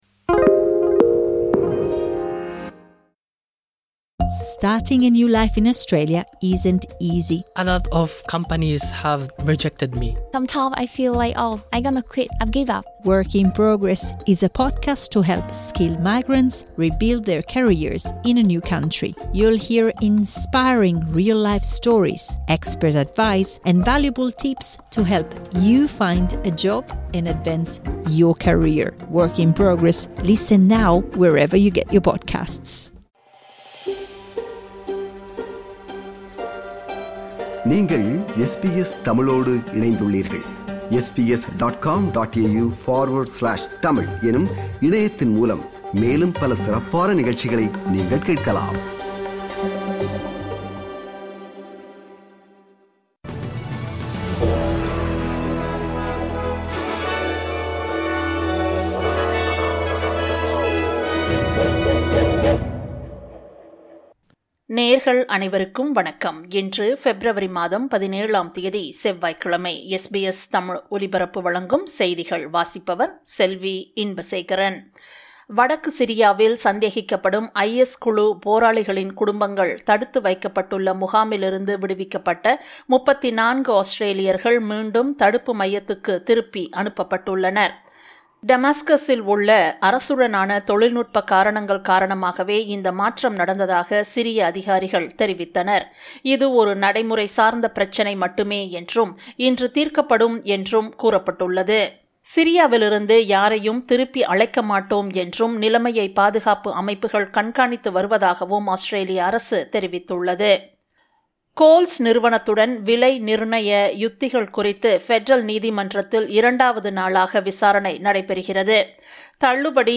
இன்றைய செய்திகள்: 17 பெப்ரவரி 2026 - செவ்வாய்க்கிழமை
SBS தமிழ் ஒலிபரப்பின் இன்றைய (செவ்வாய்க்கிழமை 17/02/2026) செய்திகள்.